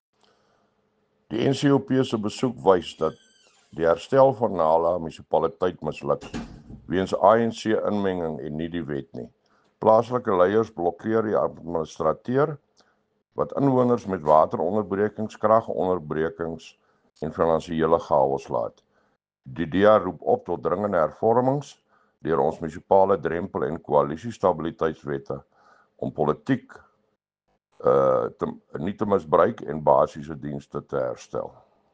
Afrikaans soundbite by Cllr Thinus Barnard, and